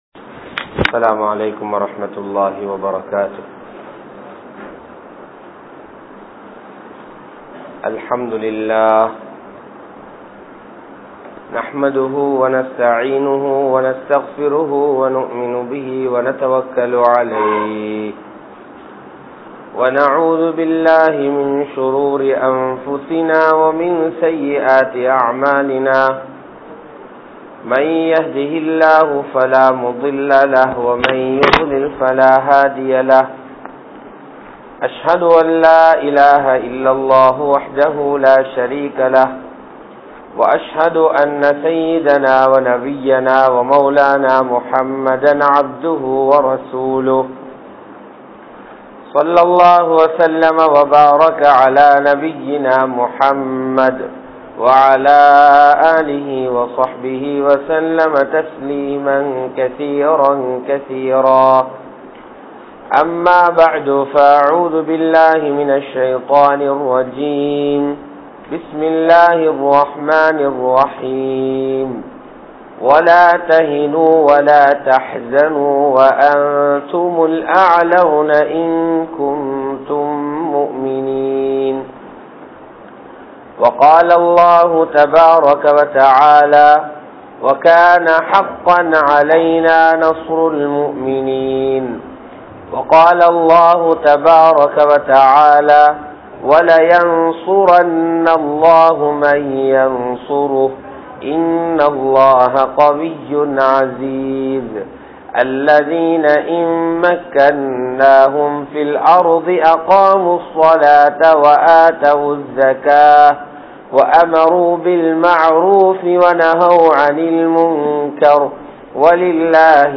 Allah`vin Uthavi | Audio Bayans | All Ceylon Muslim Youth Community | Addalaichenai
Galle, Gintota, Rahmaniya Masjith